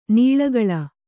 ♪ nīḷa gaḷa